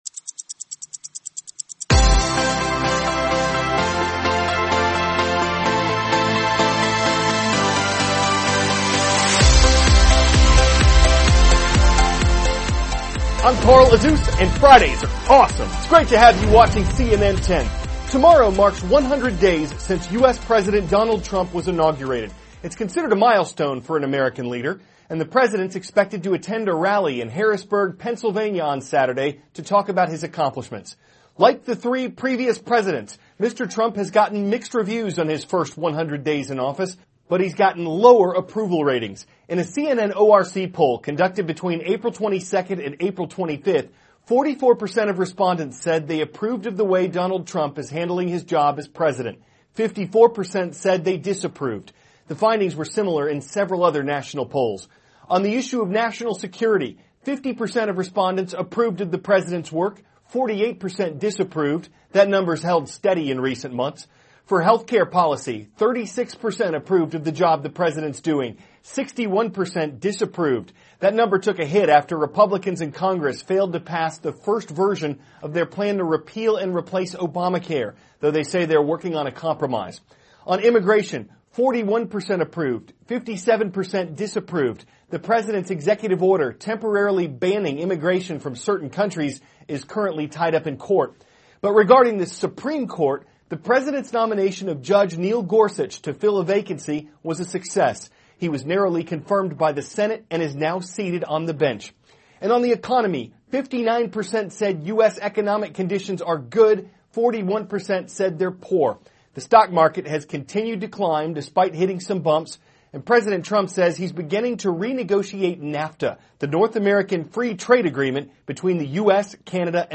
(cnn Student News) -- April 28, 2017 Events and Polls Numbers from President Trump`s First 100 Days; U.S. Fighter Jets in Eastern Europe; Concerns About Energy Drinks THIS IS A RUSH TRANSCRIPT.